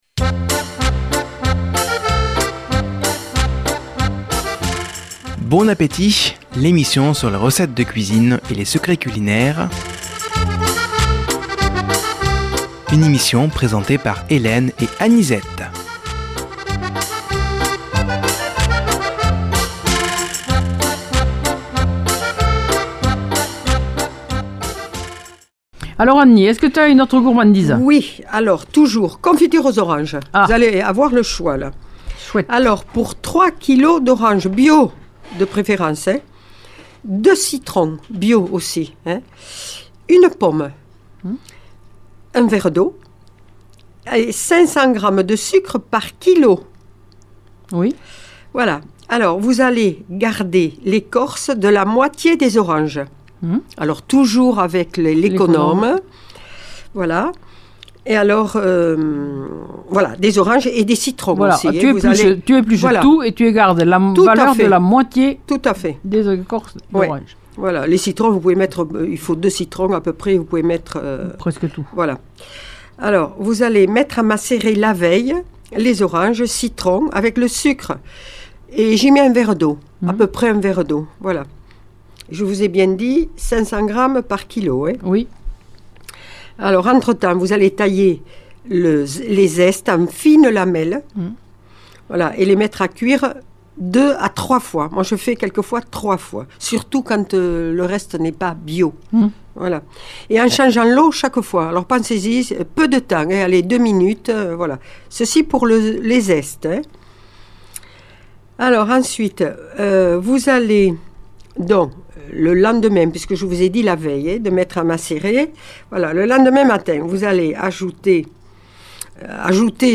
Présentatrices